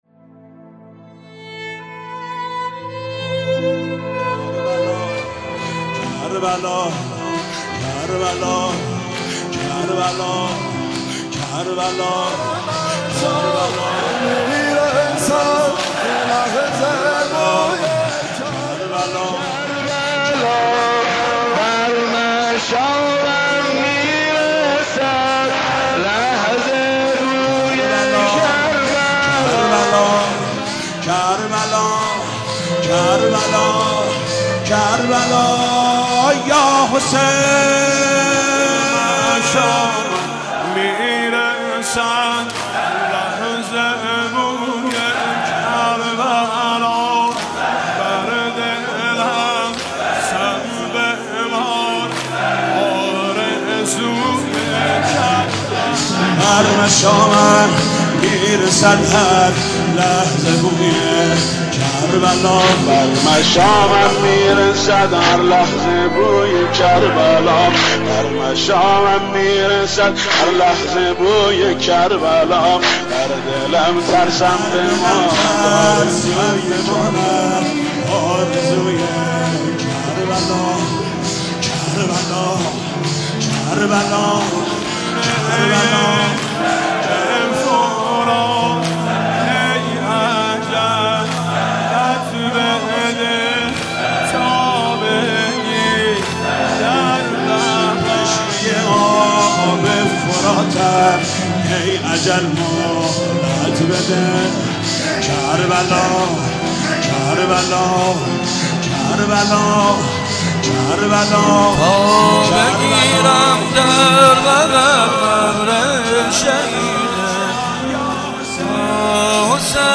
• مداحی, محرم, عاشورا, کربلا, کلیپ صوتی, بر مشامم میرسد هر لحظه بوی کربلا, پادکست